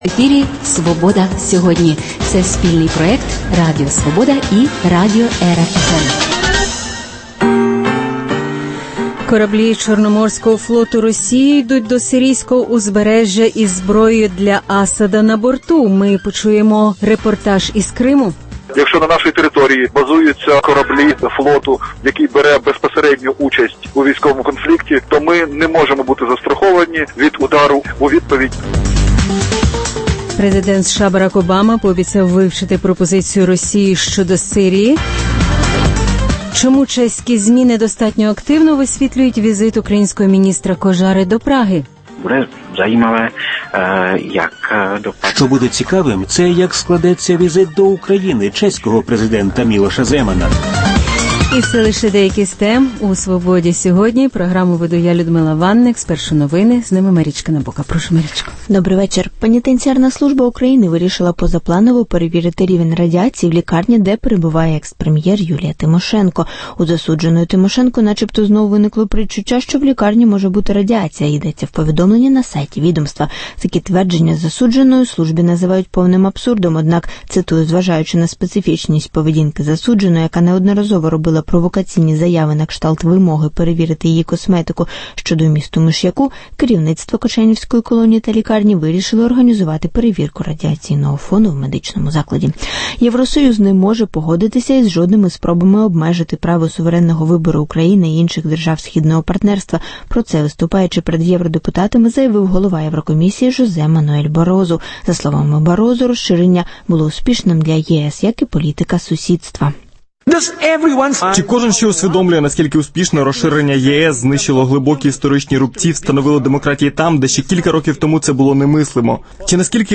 Кораблі Чорноморського флоту Росії йдуть до сирійського узбережжя із зброєю для Асада на борту? Чому чеські ЗМІ недостатньо активно висвітлюють візит українського міністра Кожари до Праги? Автор дослідження про кримських татар Ендрю Вілсон в інтерв’ю Радіо Свобода розповість про свої висновки